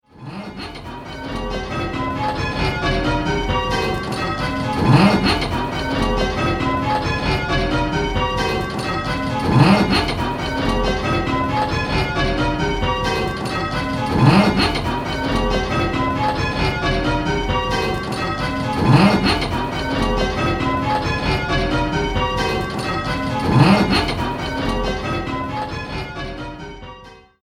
In this game you will be answering similar questions, but with 10 bells to choose from, the choices are much larger and there are more different notes to identify!